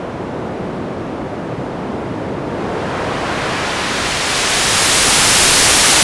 your previous code created a “gradual” low-pass filter whose cut-off frequency increased across the interval selected …
I’m after something very similar but a high-pass filter whose cut-off frequency decreases across the interval selected.
''gradual'' filters on white noise~.GIF